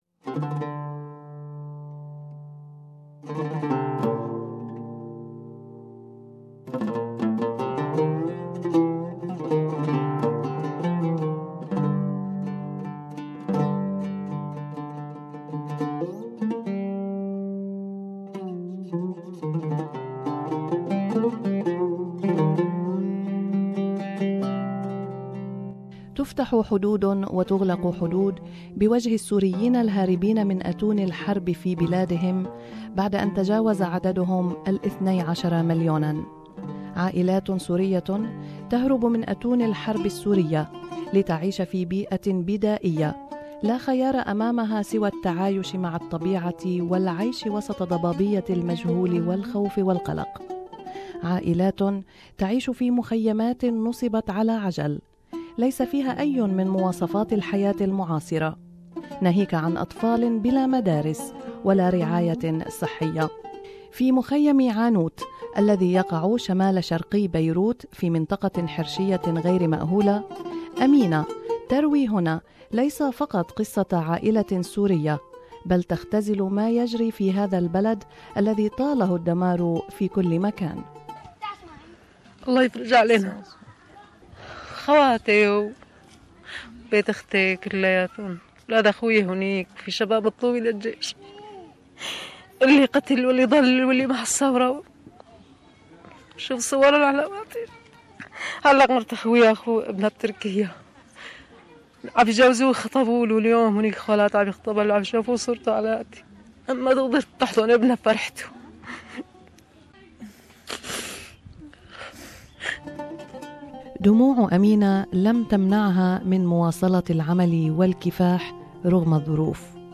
Syrian women in a refugee camp in Lebanon tell their stories